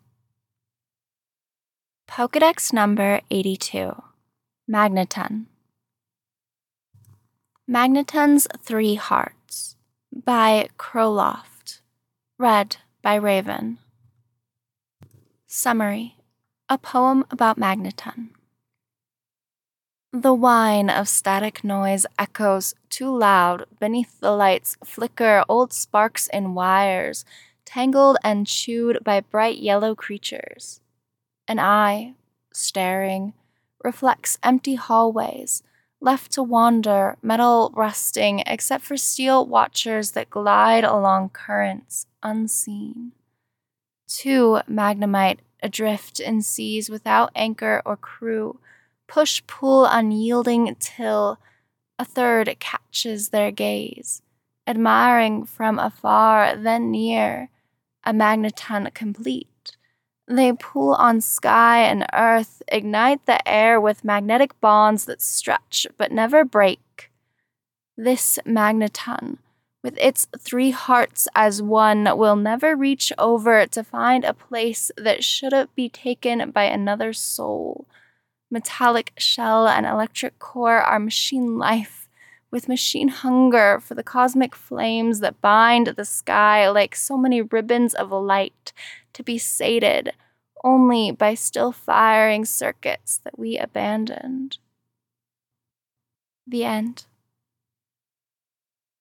[Podfic] Magneton’s Three Hearts